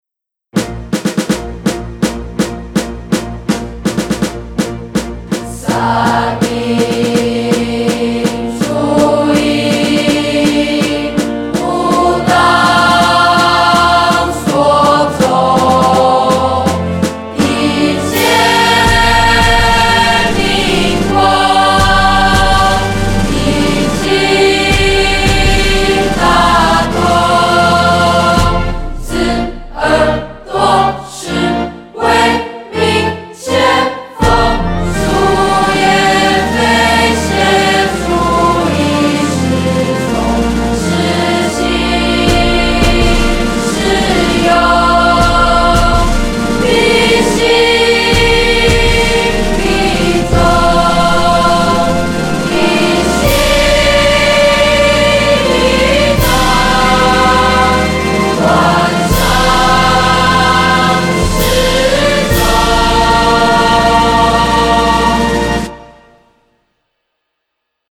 由本校合唱團演唱